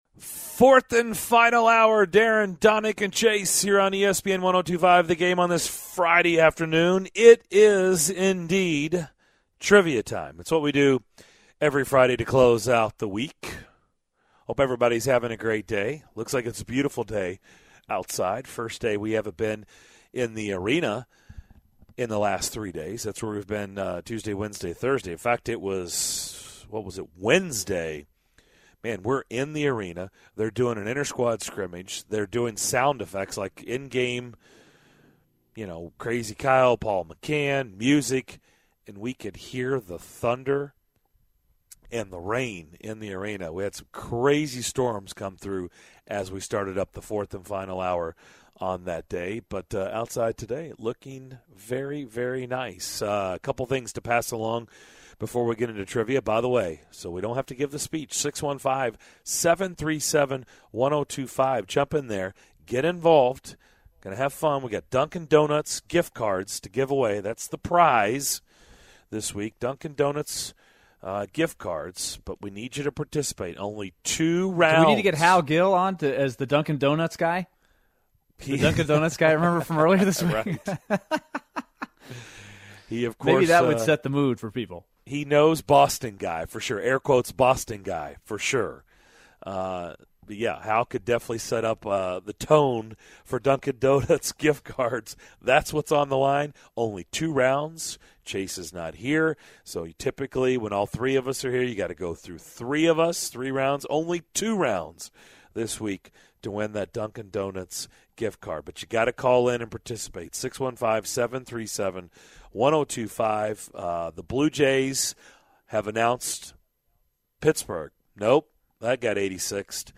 Plus our conversation with Chris Rose from the MLB Network.